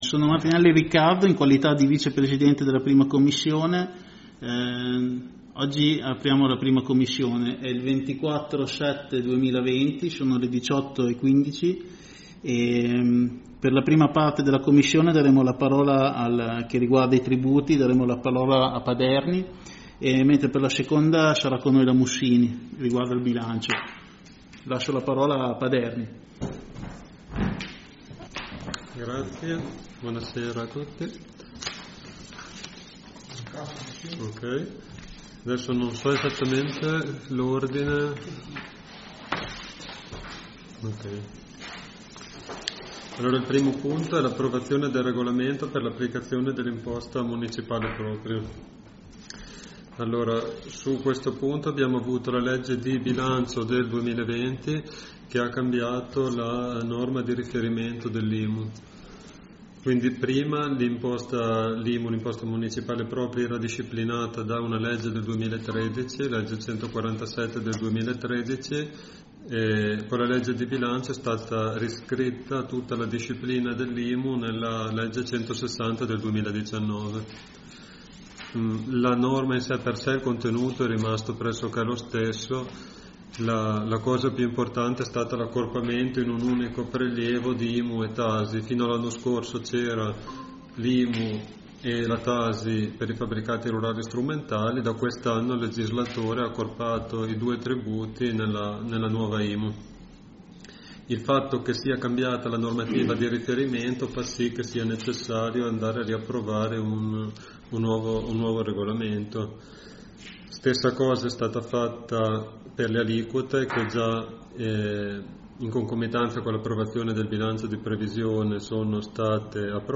Seduta 1^ Commissione 24/07/2020